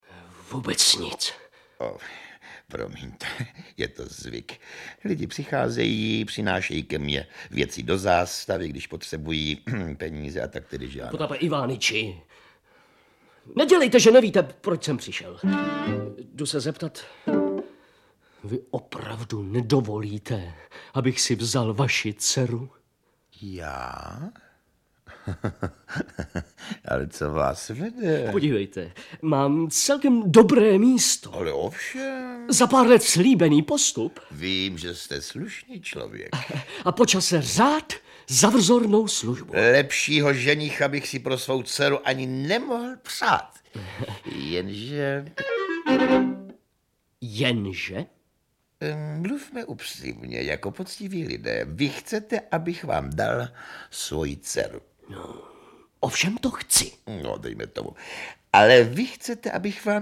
Audiobook
Audiobooks » Poetry, Classic Works